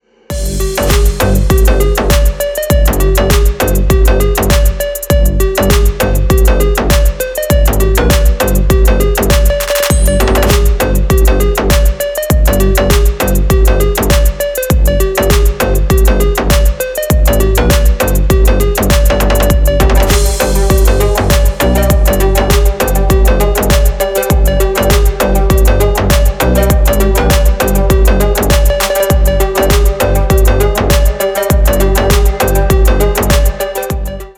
• Качество: 320, Stereo
без слов
Midtempo
G-House
звонкие